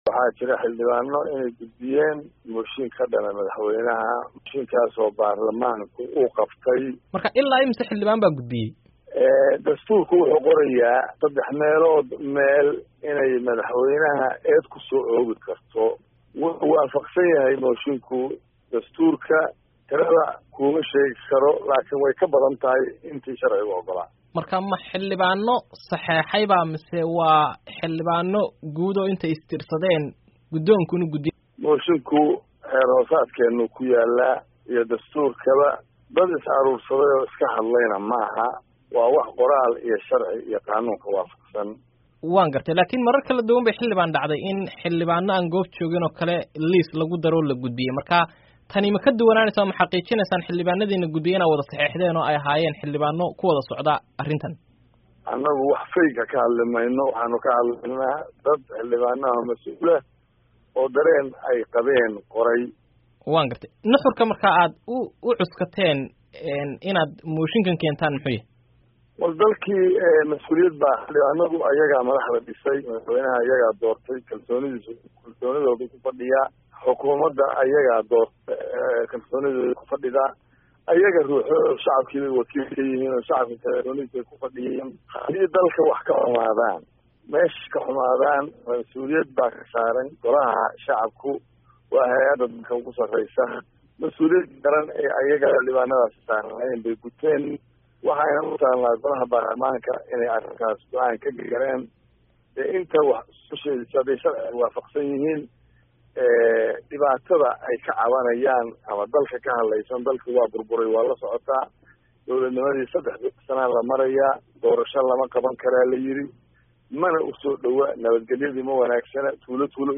Wareysiga Xildhibaan Cabdullaahi